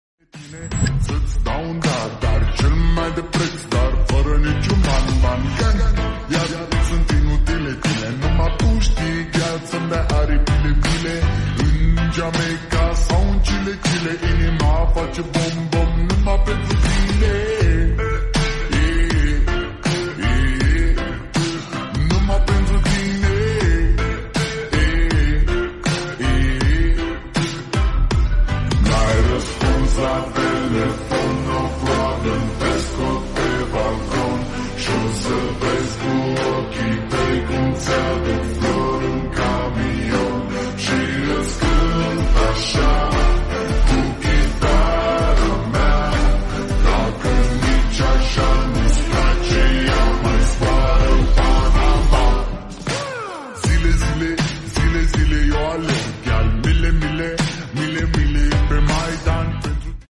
ᴋɪɴᴅʟʏ ᴜꜱᴇ ʜᴇᴀᴅᴘʜᴏɴᴇꜱ ꜰᴏʀ 8ᴅ ᴀᴜᴅɪᴏ ᴇxᴘᴇʀɪᴇɴᴄᴇ